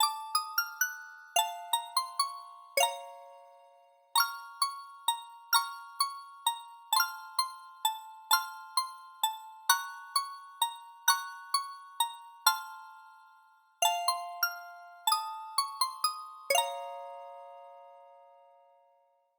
waltz.ogg